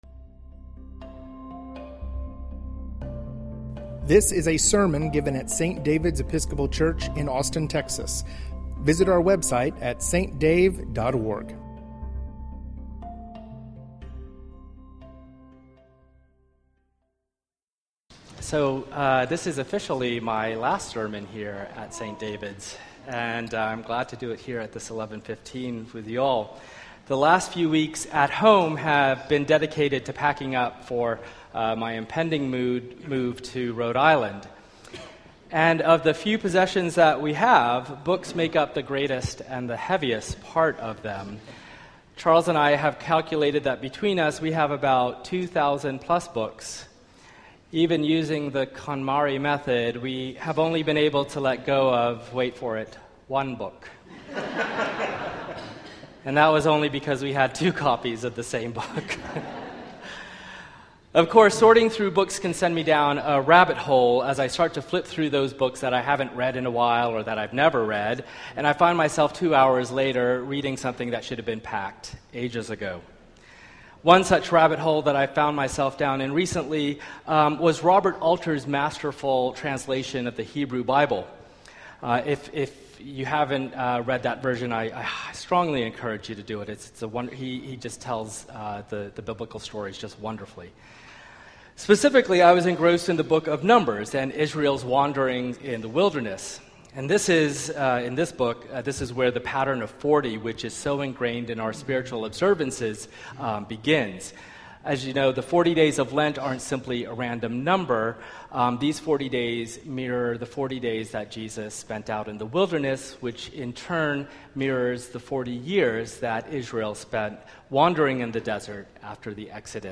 Sermon
st-david-s-episcopal-church-72124-o.mp3